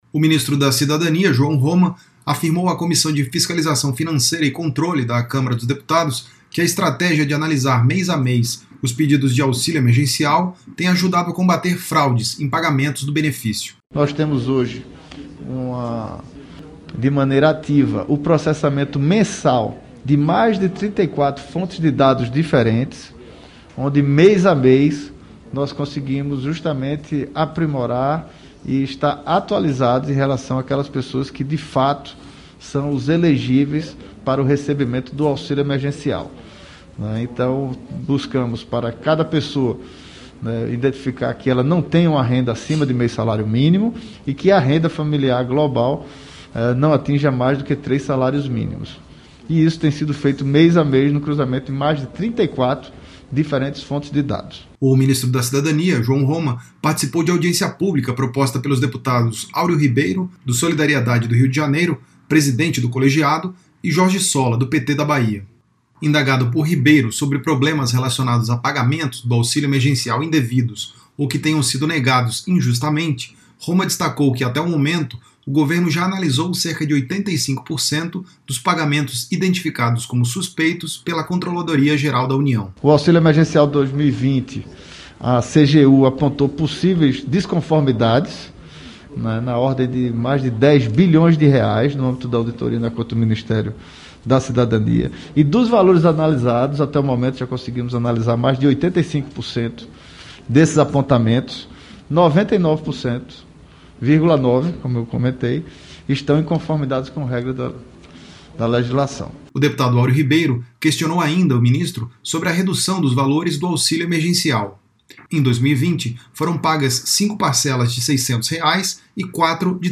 A audiência pública foi proposta pelo presidente da comissão, Aureo Ribeiro (Solidariedade-RJ), e pelo deputado Jorge Solla (PT-BA).